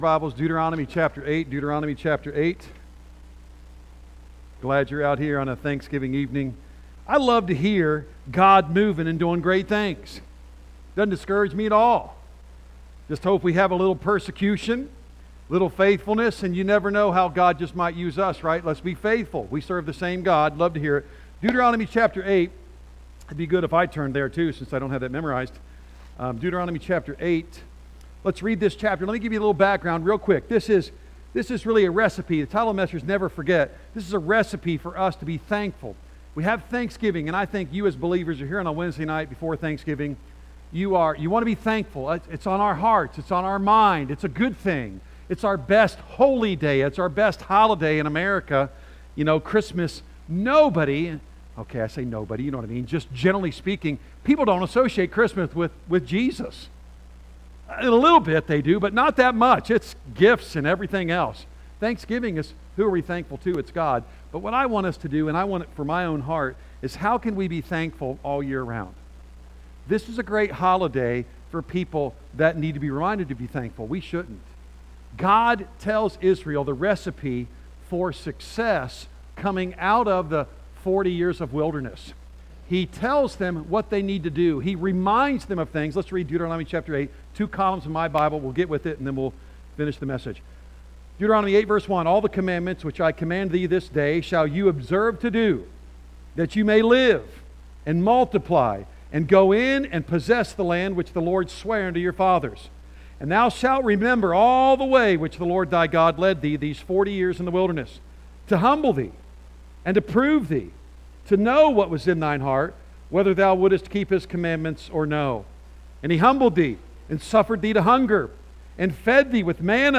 A message from the series "Standalone Sermons."